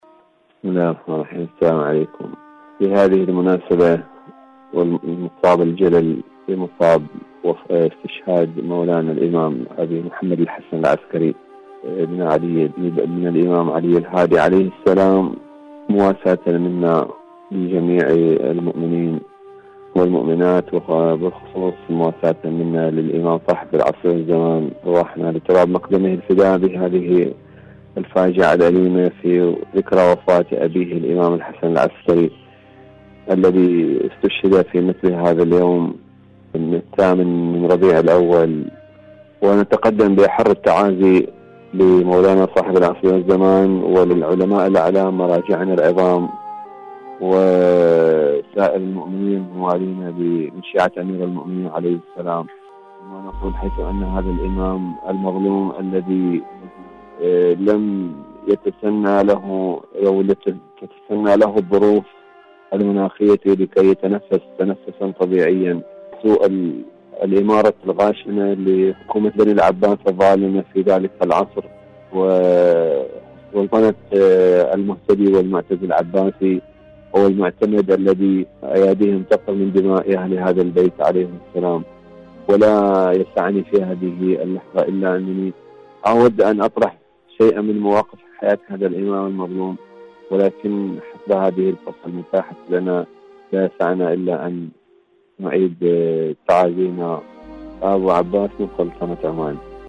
مشاركة هاتفية